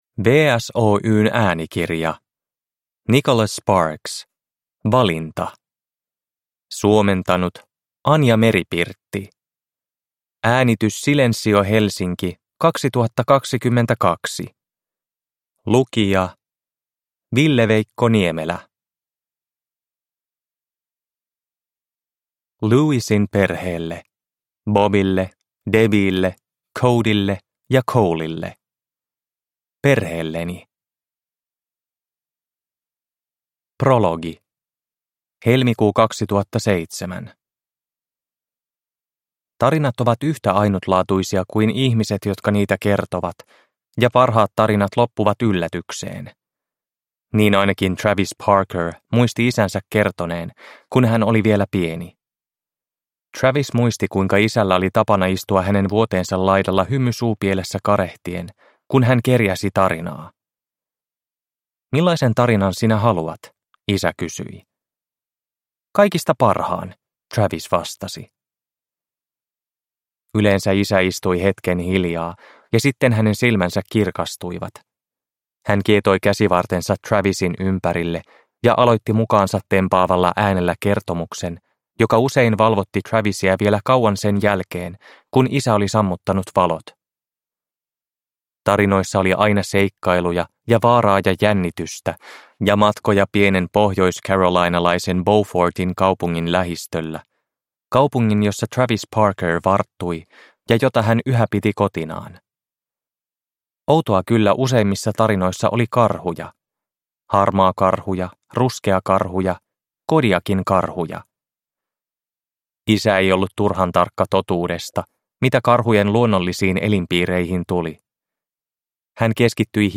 Valinta – Ljudbok – Laddas ner
Produkttyp: Digitala böcker